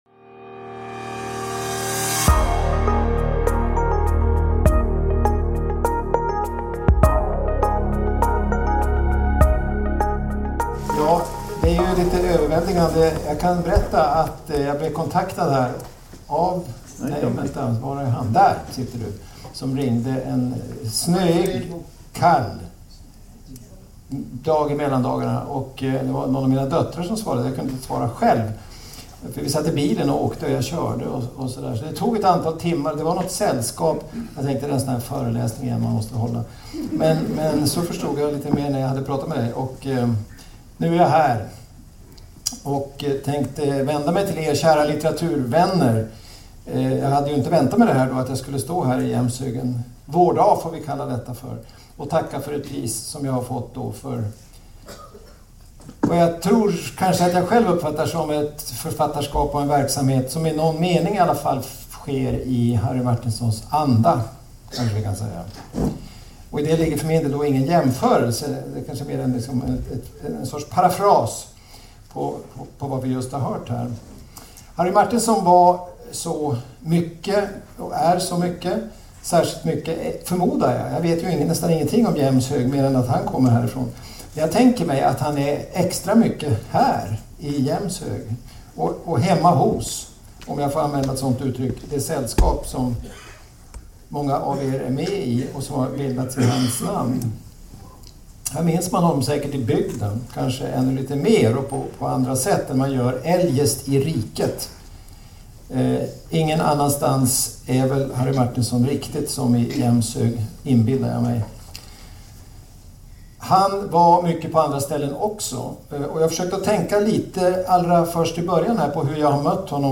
Sverker Sörlins prisföredrag i Jämshög 4 maj 2024